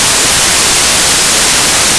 whitenoise.opus